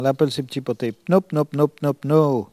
Mémoires et Patrimoines vivants - RaddO est une base de données d'archives iconographiques et sonores.
Elle crie pour appeler les canetons